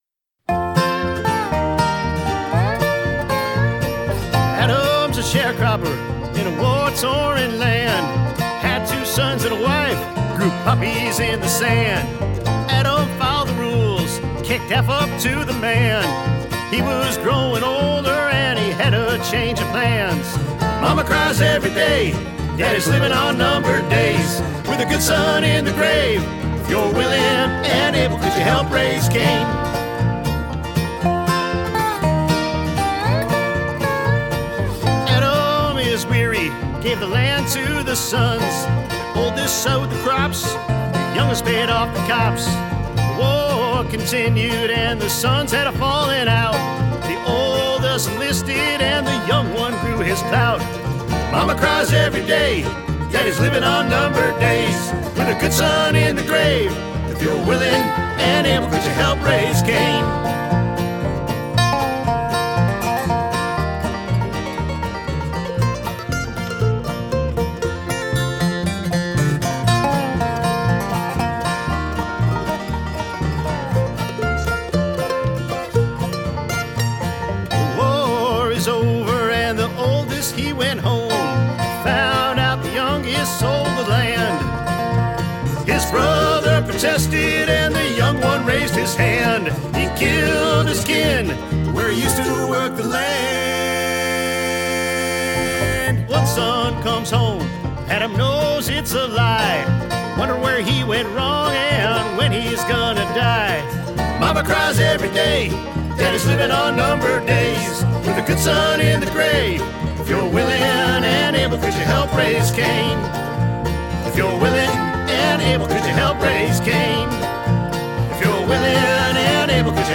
Genre: Acoustic.